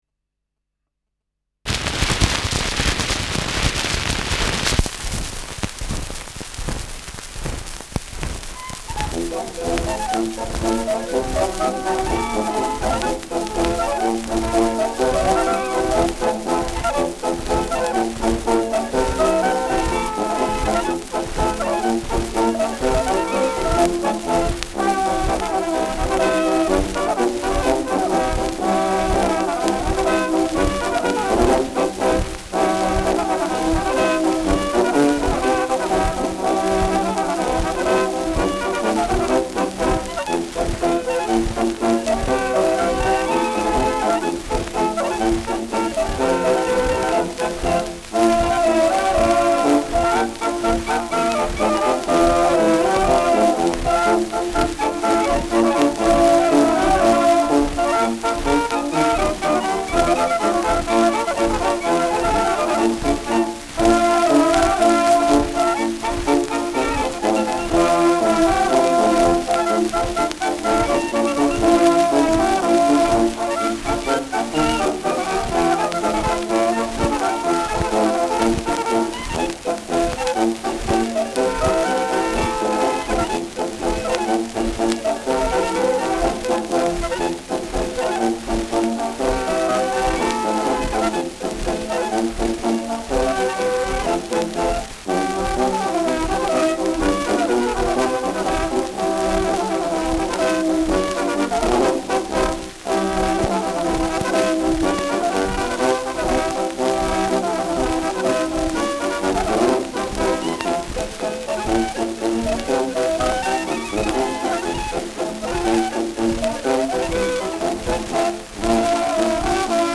Schellackplatte
Abgespielt : Durchgehendes Nadelgeräusch im unteren Frequenzbereich : Durchgehend leichtes Knacken : Keine übliche Abspielgeschwindigkeit